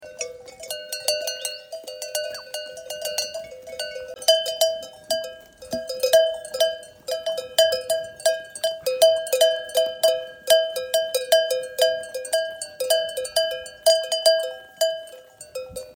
O arhivă de sunete și mirosuri, povești și obiecte din gospodărie sunt comoara Punctului de memorie colectivă care se deschide în 30 martie la Vad, județul Cluj.
Sună talanga
Sunet-talanga.mp3